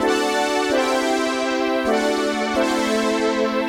AM_VictorPad_130-E.wav